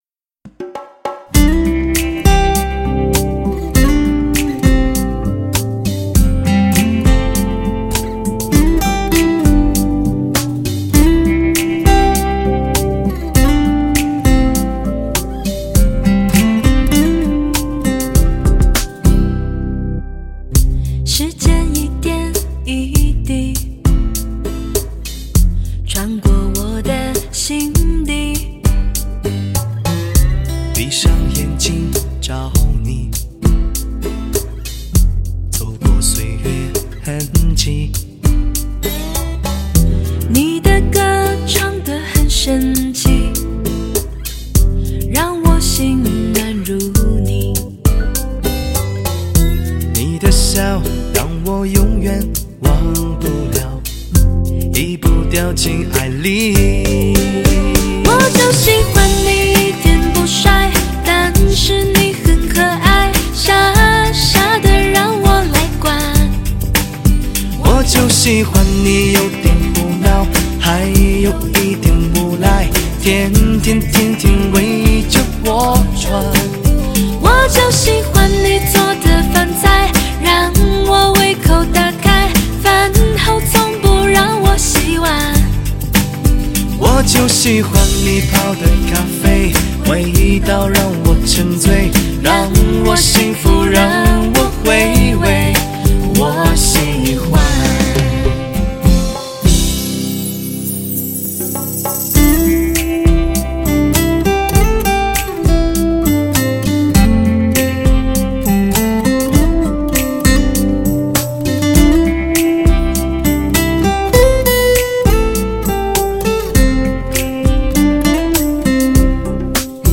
最动人的情歌，引发寂寞的共鸣，讲述爱情的纠结和美丽。